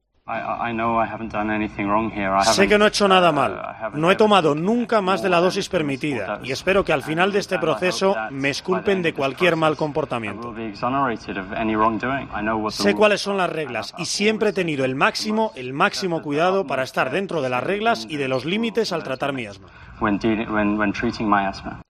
En declaraciones a ITV News
El ciclista defendió su inocencia en Alcudia (Mallorca), en un hotel donde se encuentra con su equipo y donde se ha mostrado dispuesto a colaborar para saber el por qué de su positivo.